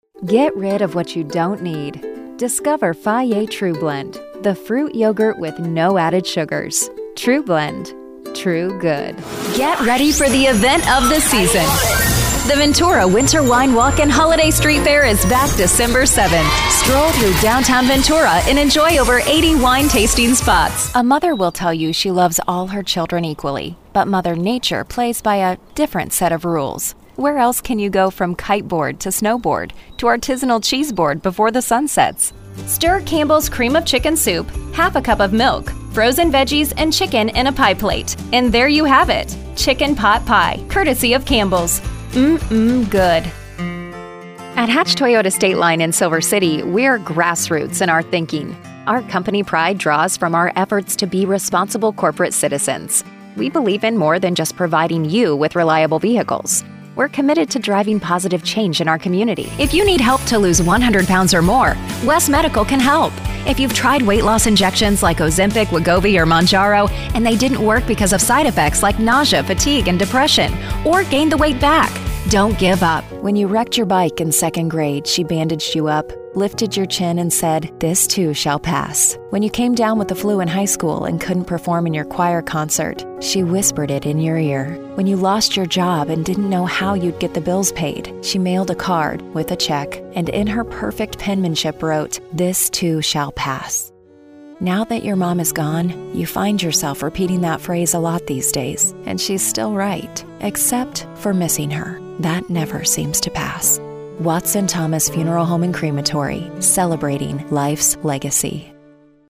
Female
My voice is youthful, upbeat, conversational and relatable.
Radio Commercials
Radio Commercial Demo
Words that describe my voice are Engaging, Conversational, Relatable.